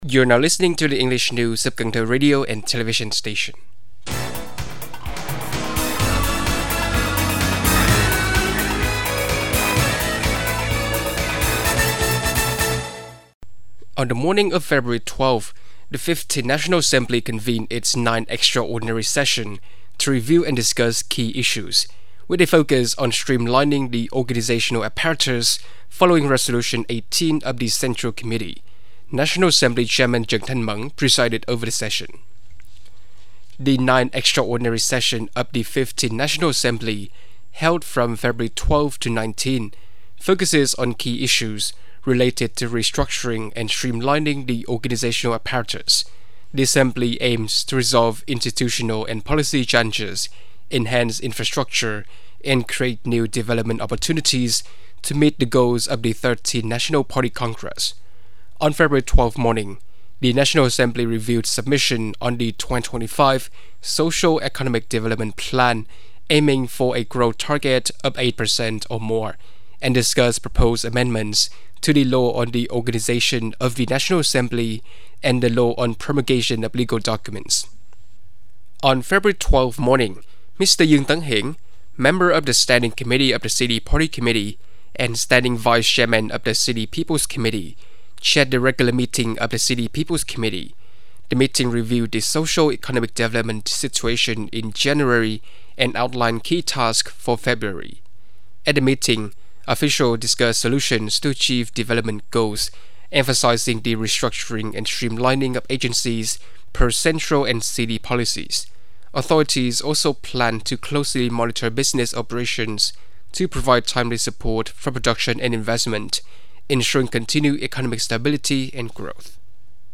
Bản tin tiếng Anh 12/2/2025